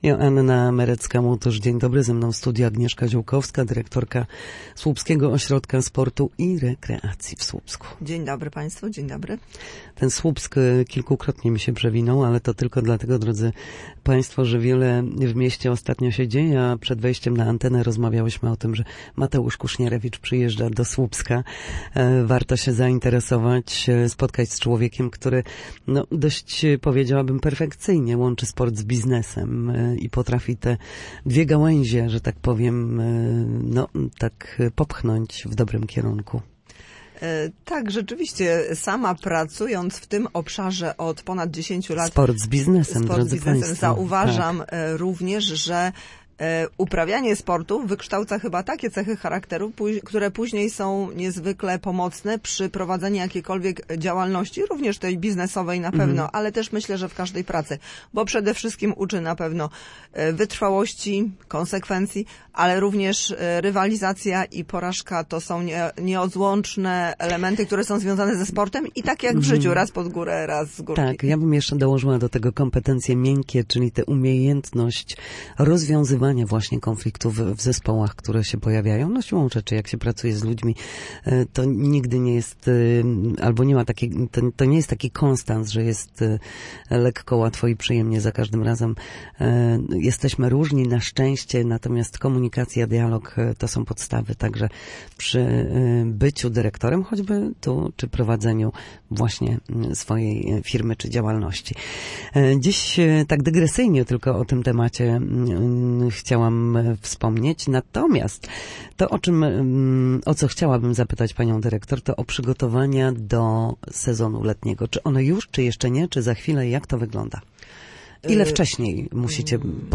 mówiła w Studiu Słupsk